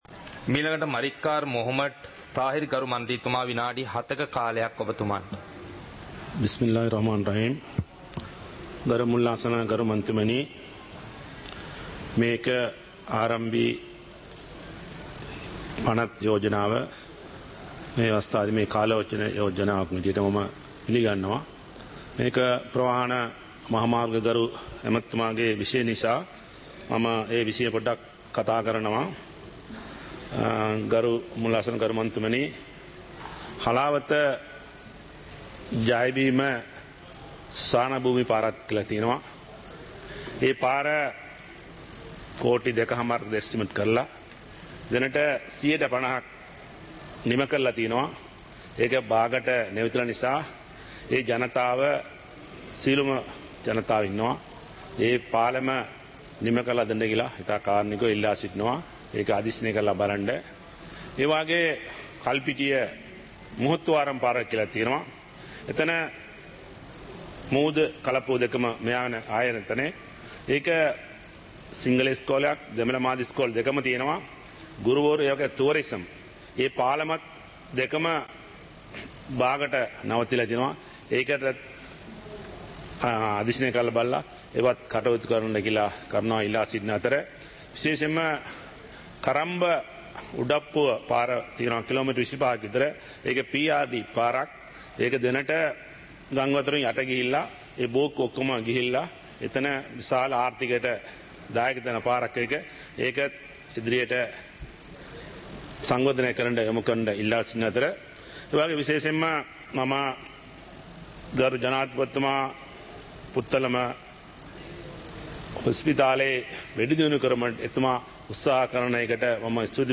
சபை நடவடிக்கைமுறை (2026-01-08)
பாராளுமன்ற நடப்பு - பதிவுருத்தப்பட்ட